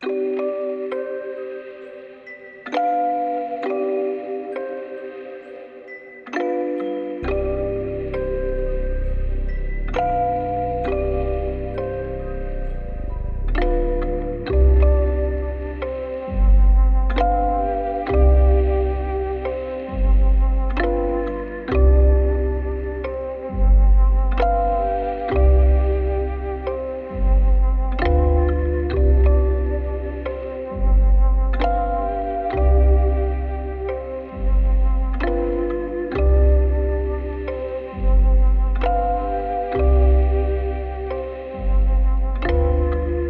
Sucker Melo (133 bpm).wav